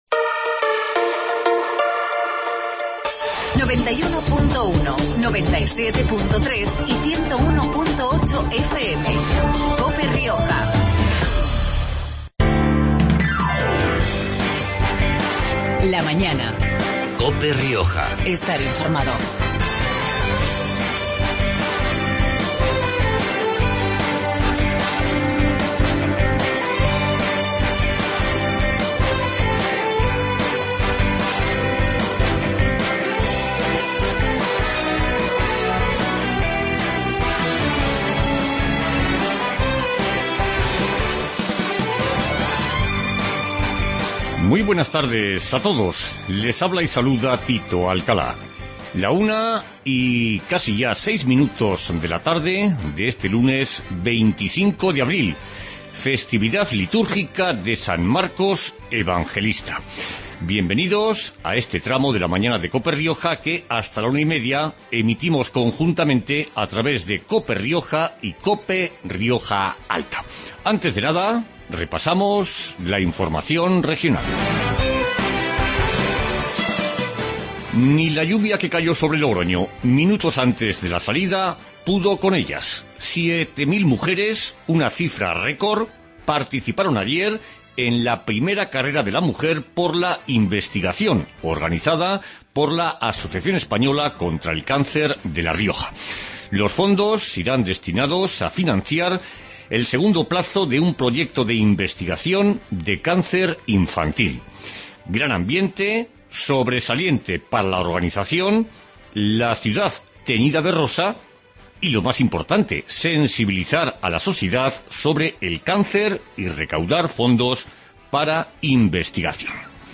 AUDIO: Magazine de actualidad riojana